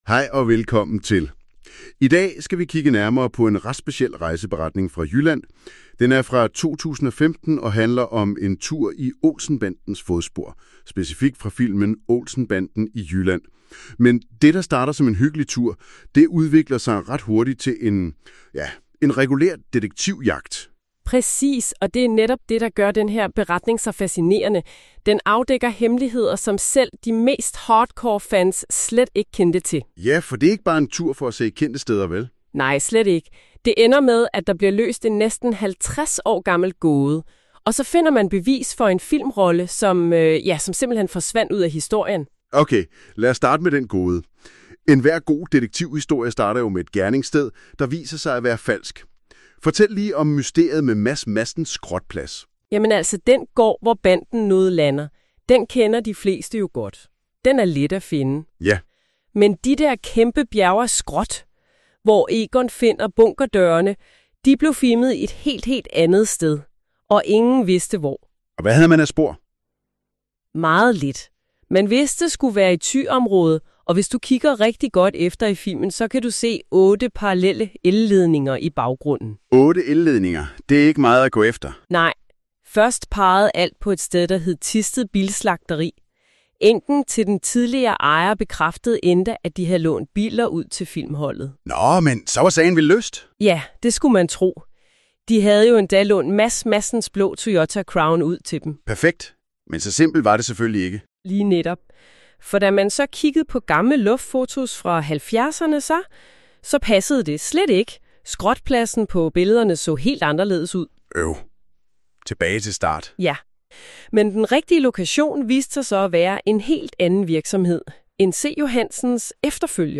Lydresumé i podcastformat
MP3 (AI-genereret lydindhold)